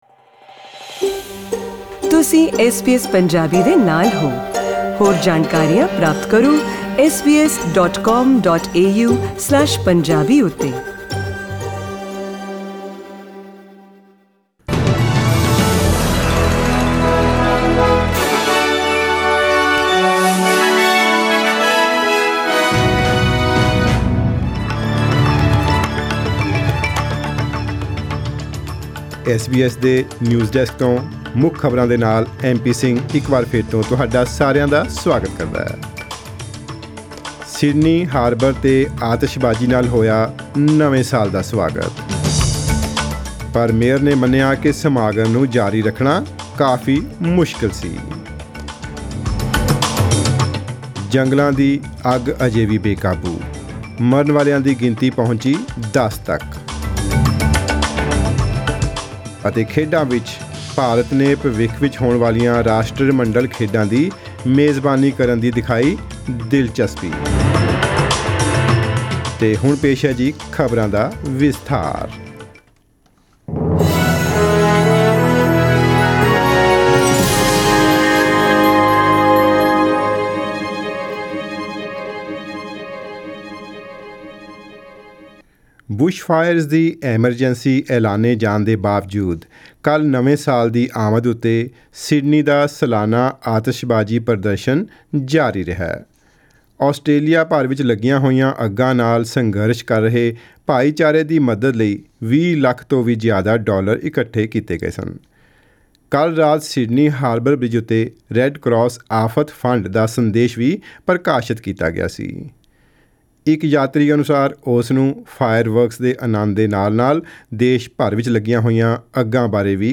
Here are the headlines in tonight’s news bulletin: The number of lives lost in the bushfires continues to grow with at least 10 deaths; New Year's Eve fireworks light up Sydney Harbour but the city's Mayor admits it was tough to proceed with the event; And in sport, India expresses interest in hosting another Commonwealth Games after calling off a planned boycott for 2022.